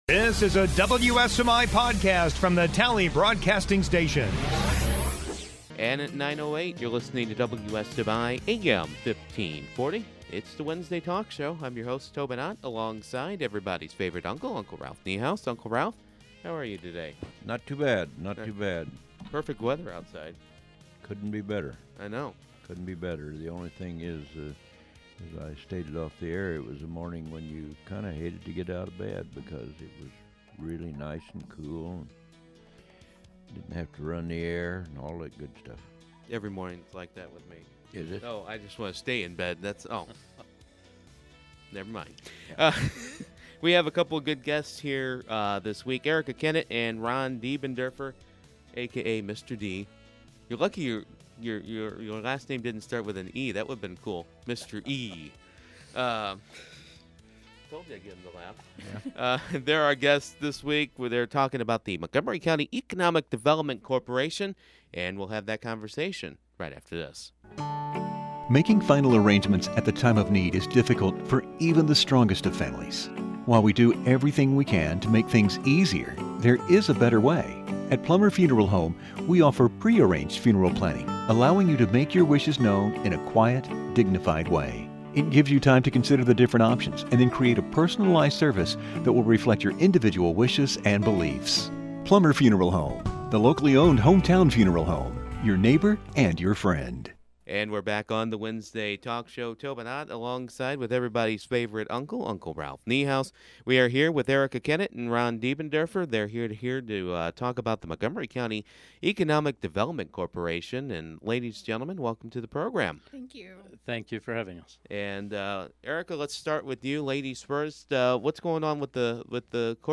The Wednesday Morning Talk Show